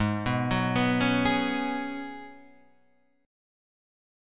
G# Minor 11th